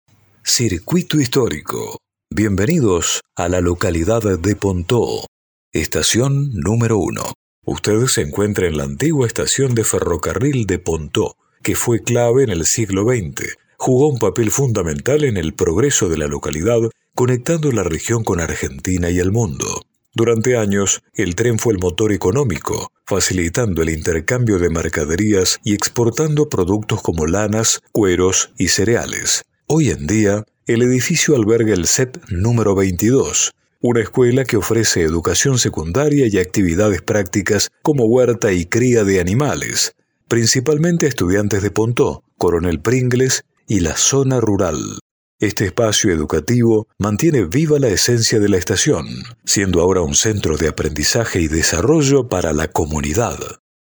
AUDIO-GUIA-PONTEAU-CIRCUITO-UNO.mp3